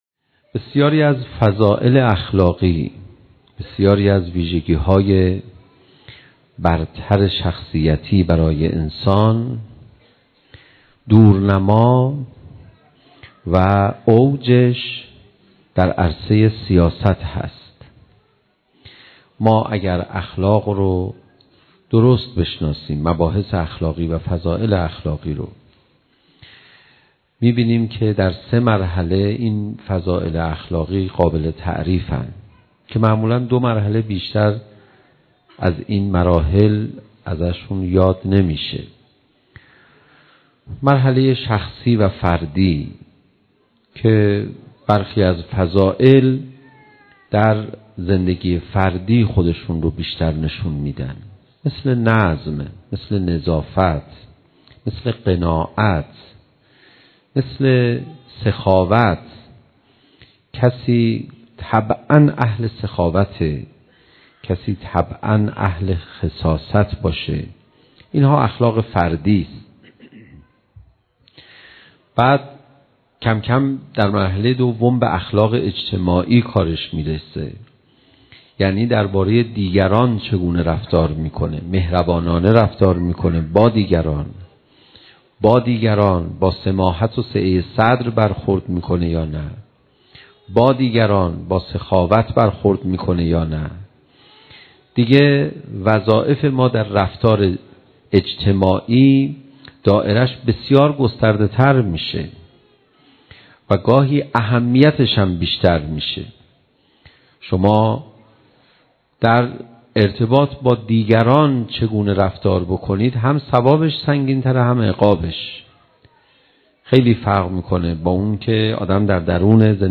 سخنرانی
شام میلاد پیامبر اکرم و امام صادق علیهما السلام